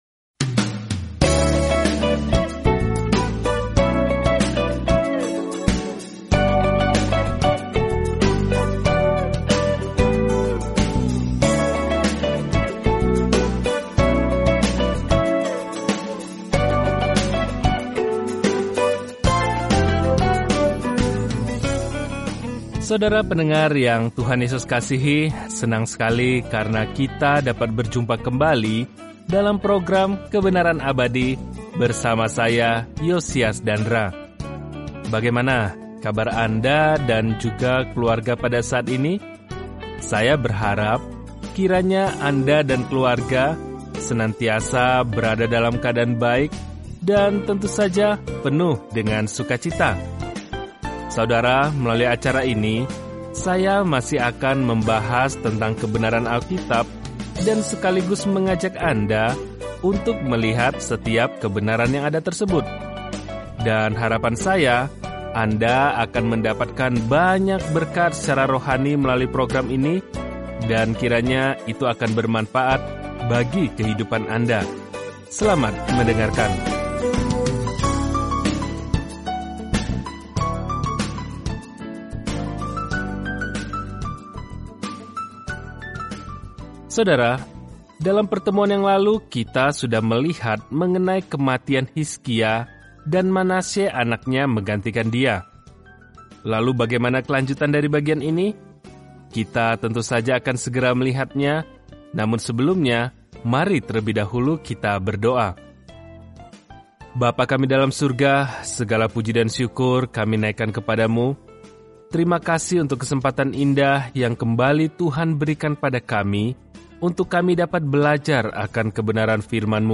Firman Tuhan, Alkitab 2 Tawarikh 33 Hari 15 Mulai Rencana ini Hari 17 Tentang Rencana ini Dalam 2 Tawarikh, kita mendapatkan sudut pandang berbeda mengenai kisah-kisah yang kita dengar tentang raja-raja dan nabi-nabi Israel di masa lalu. Perjalanan harian melalui 2 Tawarikh sambil mendengarkan studi audio dan membaca ayat-ayat tertentu dari firman Tuhan.